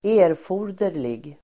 Ladda ner uttalet
erforderlig adjektiv, necessary , requisite Uttal: [²'e:rfo:r_der_lig el. ²'ä:-] Böjningar: erforderligt, erforderliga Synonymer: behövlig, nödvändig Definition: som krävs, behövlig Exempel: erforderligt material (necessary material)
erforderlig.mp3